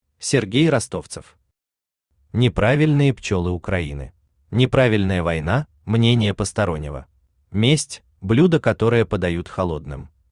Aудиокнига Неправильные «пчёлы» Украины Автор Сергей Юрьевич Ростовцев Читает аудиокнигу Авточтец ЛитРес.